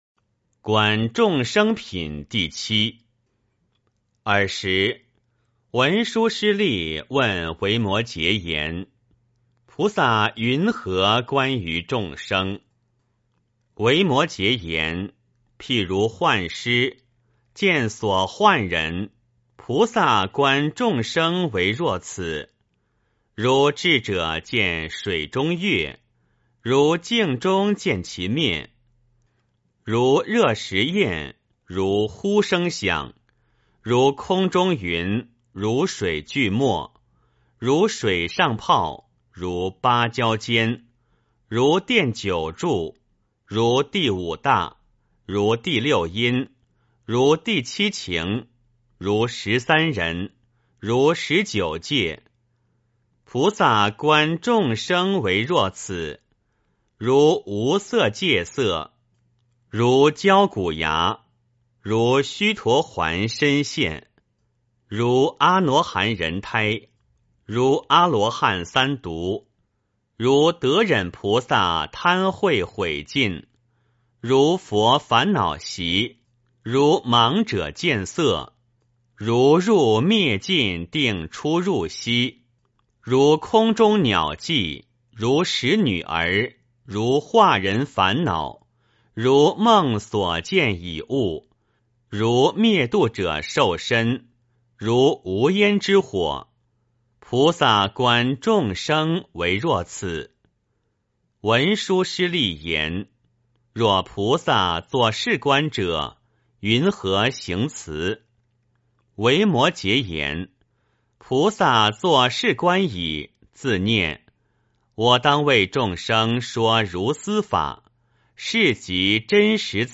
维摩诘经-观众生品第七 诵经 维摩诘经-观众生品第七--未知 点我： 标签: 佛音 诵经 佛教音乐 返回列表 上一篇： 维摩诘经-菩萨品第四 下一篇： 维摩诘经-佛道品第八 相关文章 南海普陀山观自在菩萨--普陀山僧团 南海普陀山观自在菩萨--普陀山僧团...